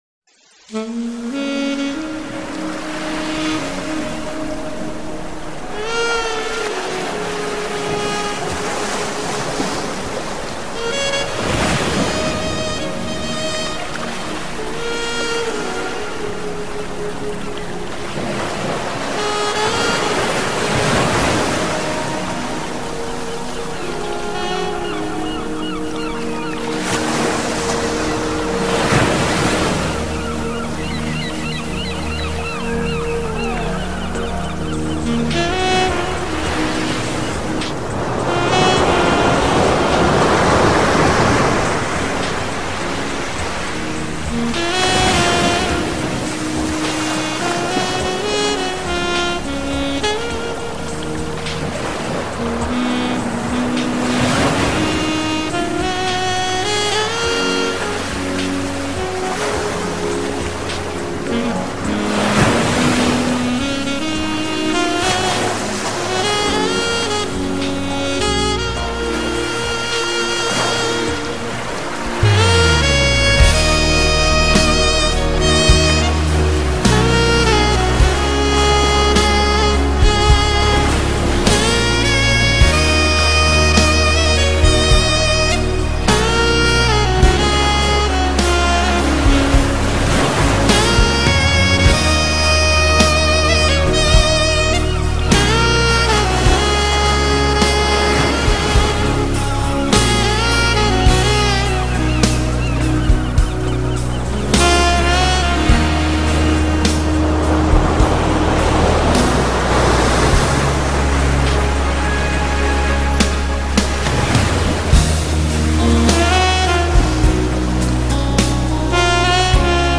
Dlya_tebyai___SHum_morya_i_krasivaya_muzyka_www_hotplayer_.mp3